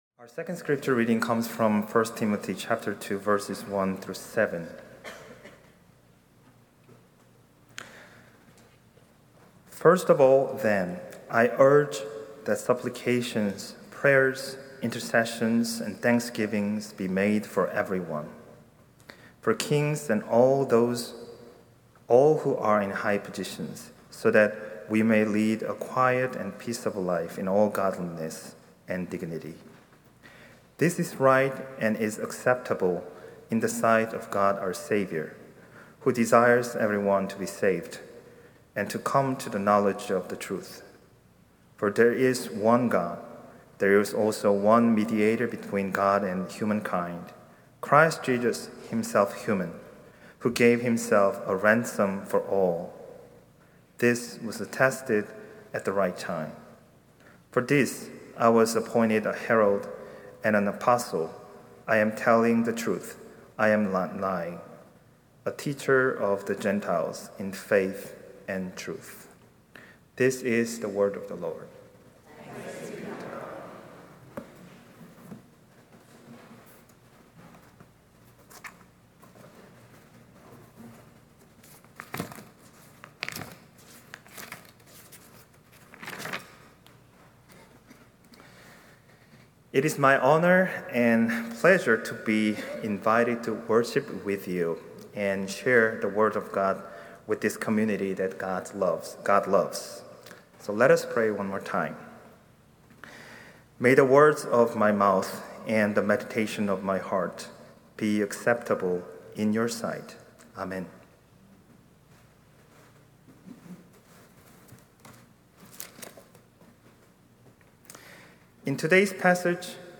Sermon+9-21-25.mp3